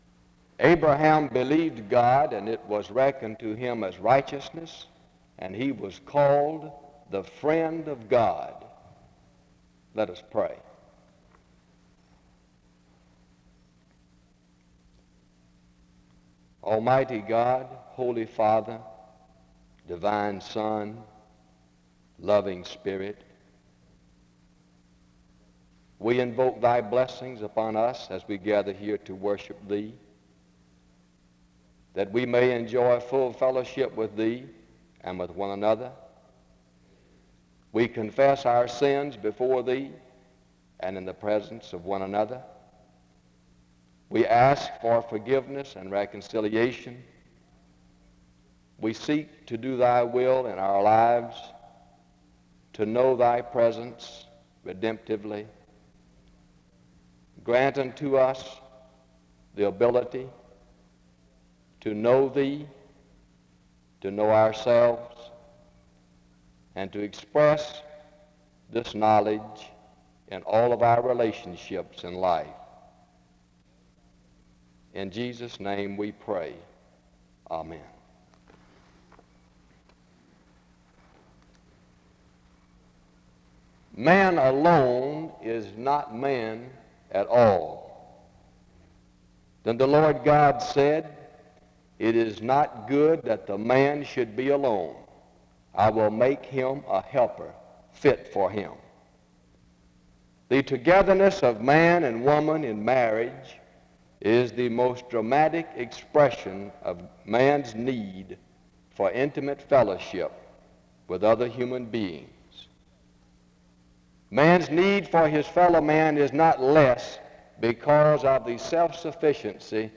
The service begins with an introduction and prayer (0:00-1:27).